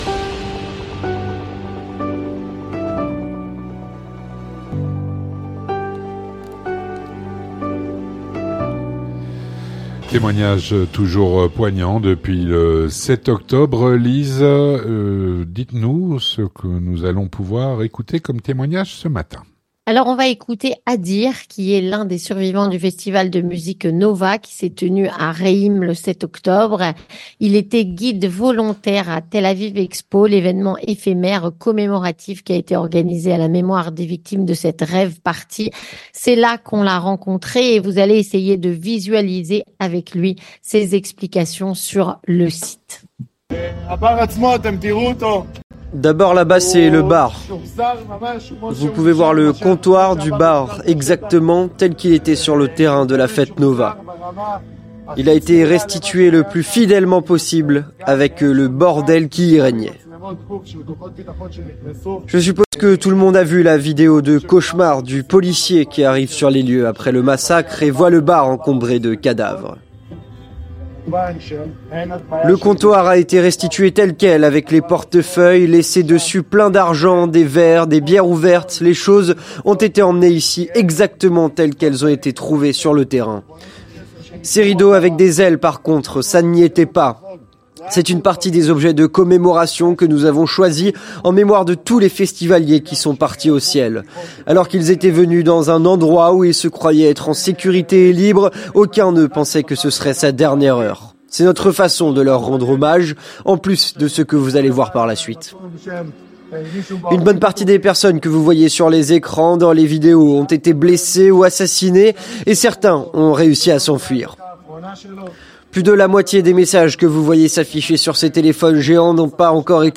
Témoignage
Un reportage